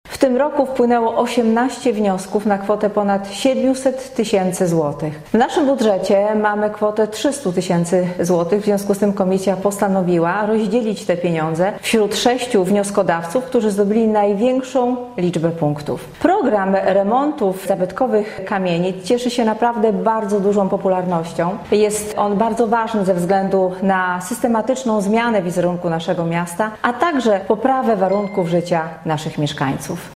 – Cieszy nas to ogromne zainteresowanie możliwością dotowania remontu zabytków, ale w zaistniałej sytuacji musimy pieniądze przeznaczone na ten cel podzielić inaczej – tłumaczy Danuta Madej, burmistrz Żar: